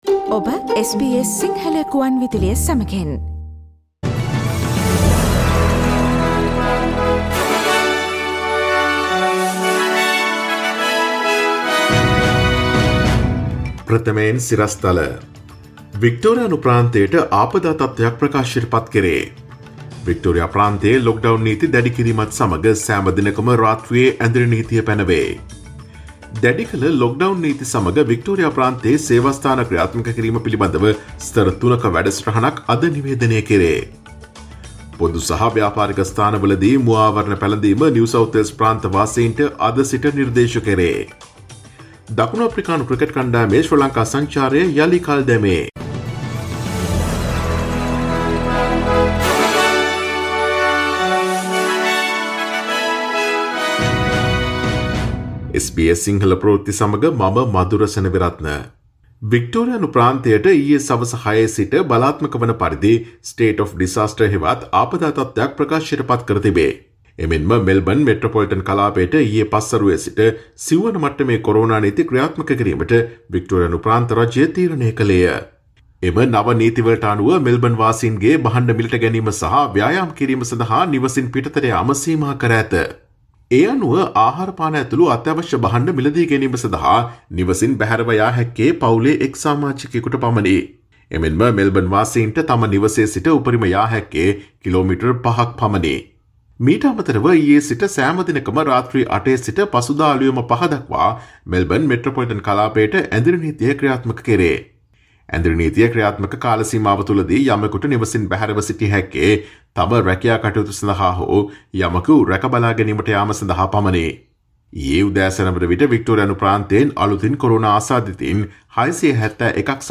Daily News bulletin of SBS Sinhala Service: Monday 03 August 2020